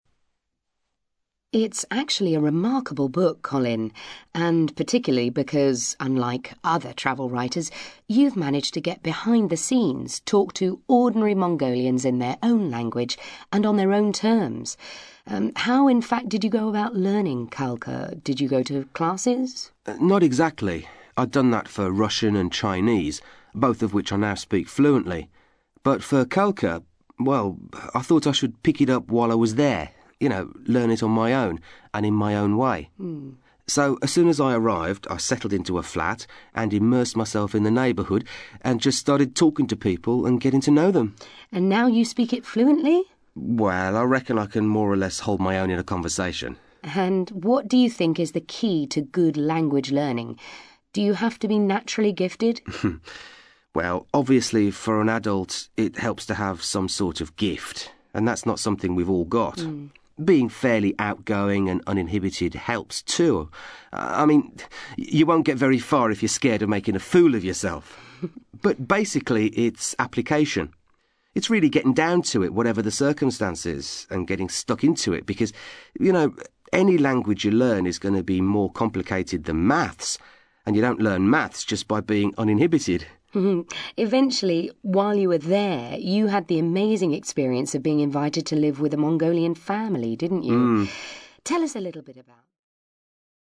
ACTIVITY 148: You are going to hear an interview on a travel programme with a writer who has been to Mongolia.